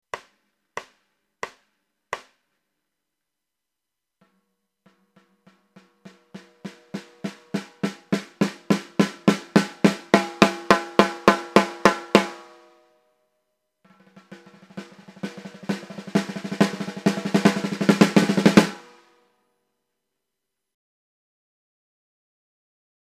Auf Bitten in meinem Marschtrommelkabinett habe ich heute mal meine 5 Snaredrums aufgenommen.
D - GMS Maple 12x7
Die GMS hätte ich erkannt, da sie den schwächsten Click-Sound hat.